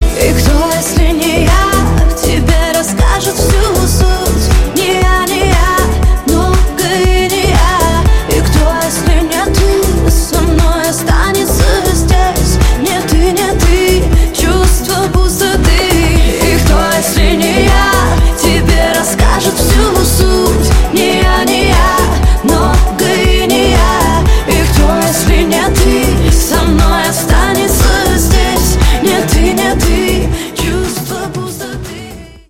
• Качество: 128, Stereo
поп
громкие
грустные
красивый женский вокал